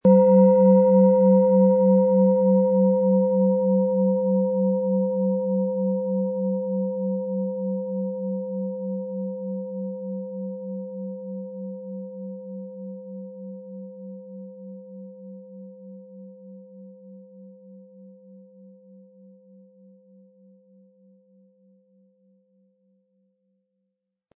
Diese tibetanische Biorhythmus Geist Planetenschale kommt aus einer kleinen und feinen Manufaktur in Indien.
Im Audio-Player - Jetzt reinhören hören Sie genau den Original-Klang der angebotenen Schale. Wir haben versucht den Ton so authentisch wie machbar aufzunehmen, damit Sie gut wahrnehmen können, wie die Klangschale klingen wird.
PlanetentonBiorythmus Geist
MaterialBronze